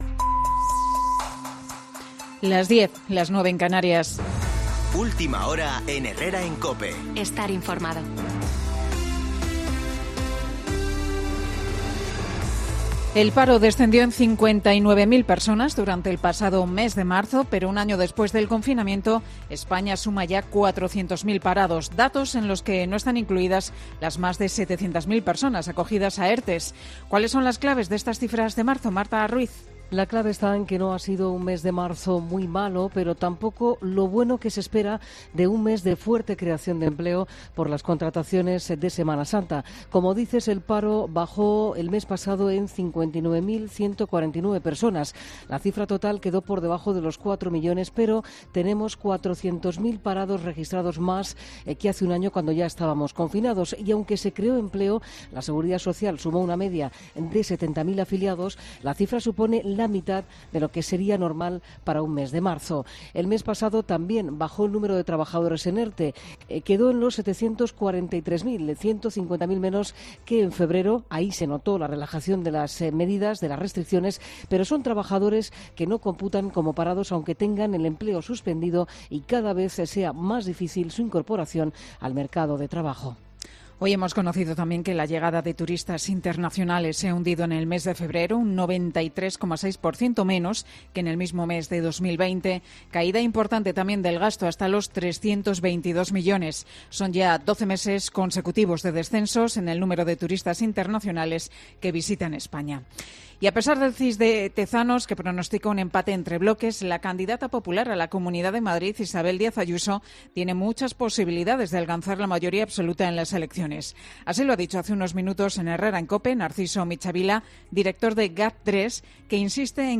Boletín de noticias COPE del 6 de abril de 2021 a las 10.00 horas